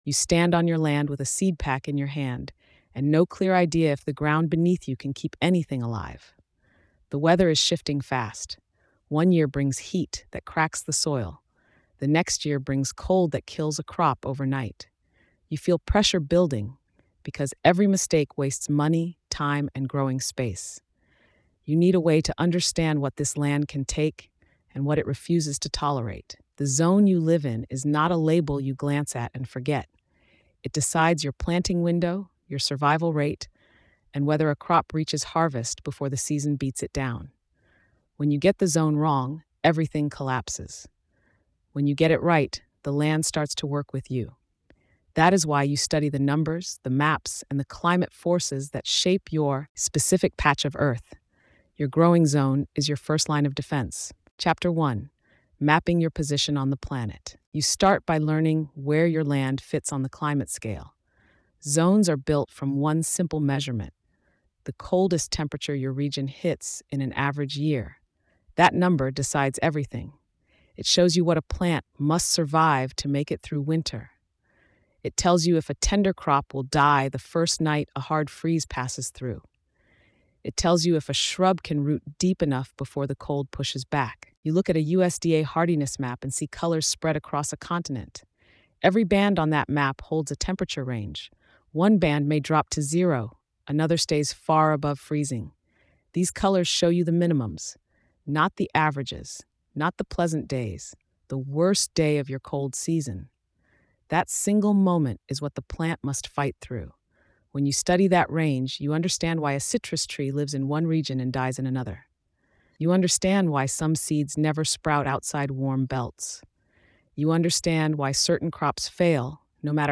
This guide takes you inside the realities of growing zones across the United States, the Caribbean, and Canada, showing how climate extremes shape every planting decision you make. Through a gritty, second-person instructional tone, you learn how to read your land’s true limits—cold snaps, heat waves, microclimates, storms, humidity shifts, and freeze cycles—and how these forces determine whether your crops live or die.